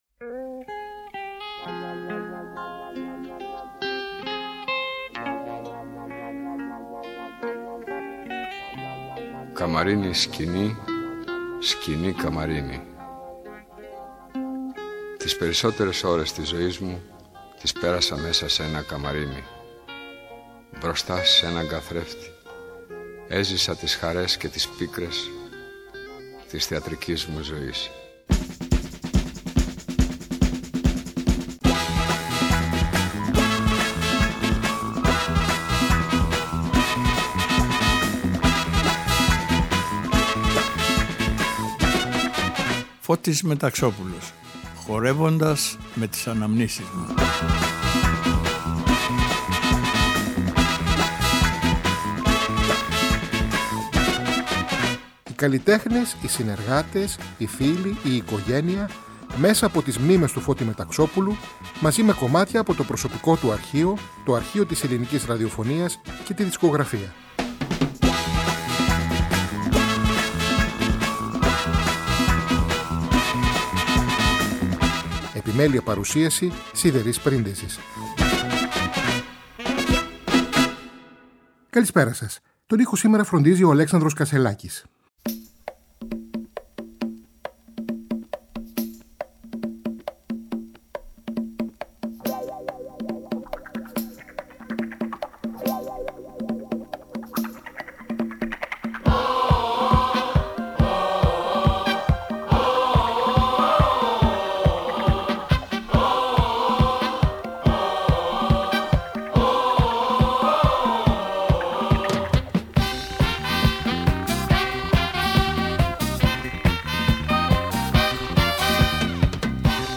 ΔΕΥΤΕΡΟ ΠΡΟΓΡΑΜΜΑ Αφιερώματα Μουσική Συνεντεύξεις